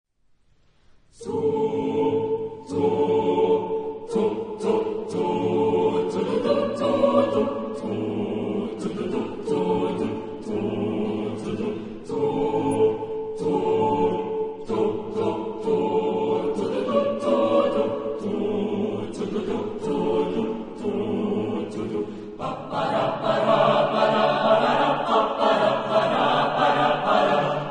Genre-Style-Form: Musical ; Secular
Type of Choir: SAATTBB  (7 mixed voices )
Soloist(s): Soprane (2)  (2 soloist(s))
Tonality: A major
Consultable under : Jazz Vocal Acappella